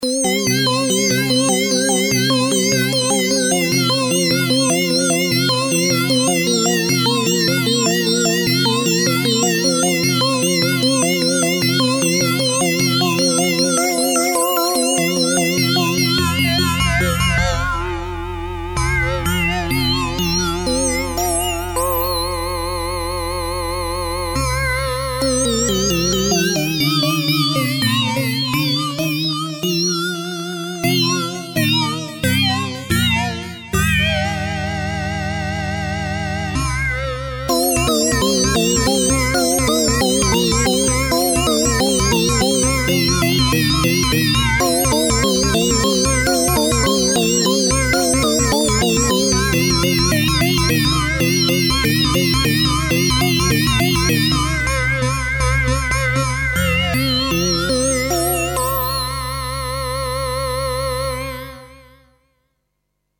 2 x waveriders, using my kenton pro 4 to use them both as a duo-synth. so this is 2 x waveriders into 2 x system x vca's, controlled by 2 x loopenv generators